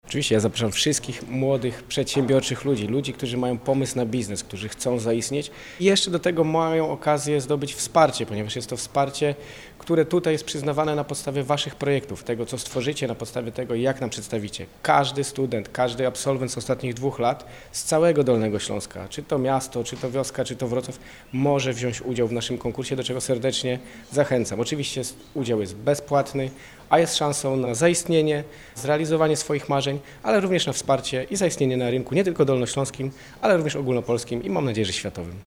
– Konkurs ma na celu wsparcie naszych dolnośląskich studentów i innowatorów w realizacji ich biznesu. Chcemy dotrzeć do mieszkańców całego Dolnego Śląska – mówi Michał Rado, wicemarszałek województwa dolnośląskiego.